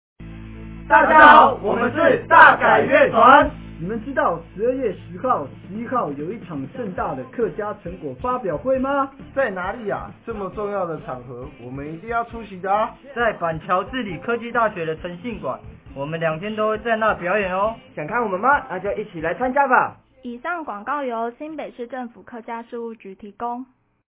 105年HAKKA新北市客家文化研習成果發表會-30秒廣播(華語) | 新北市客家文化典藏資料庫